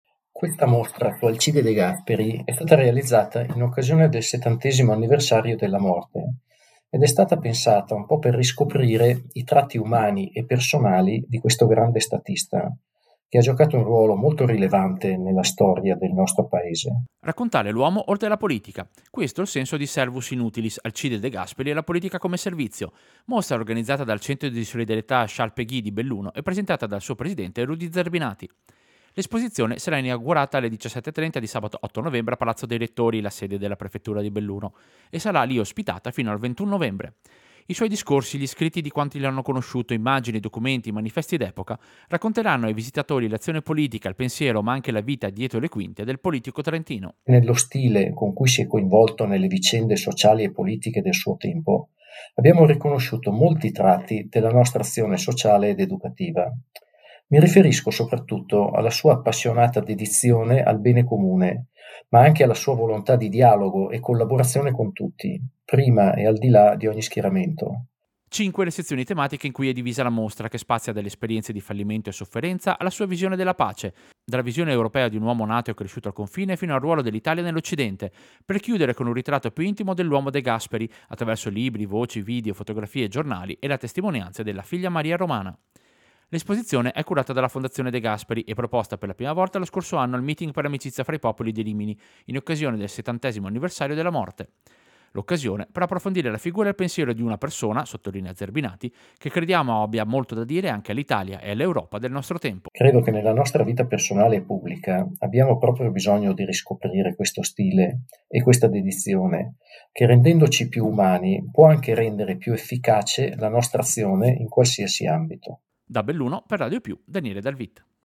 Servizio-Mostra-De-Gasperi-Prefettura.mp3